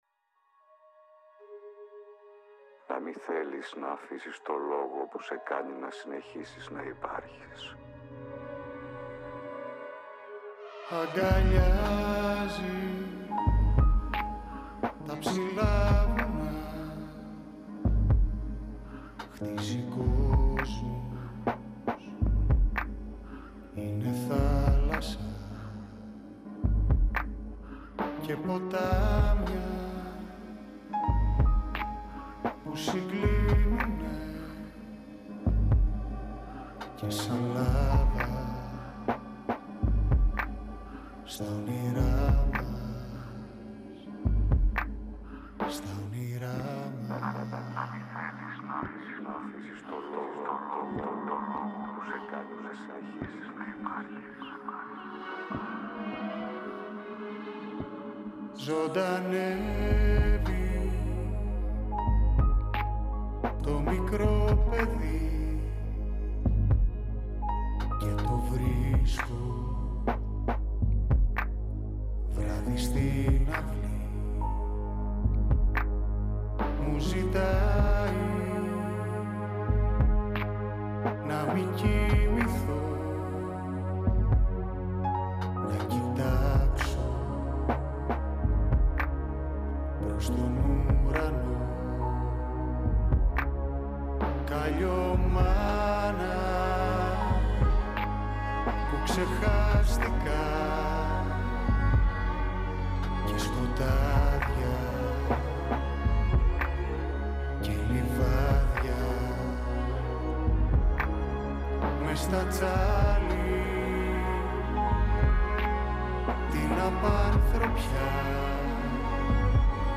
Η συνέντευξη πραγματοποιήθηκε στις 19/2/2024 εκπομπή “καλημέρα” στον 9,58fm της ΕΡΤ3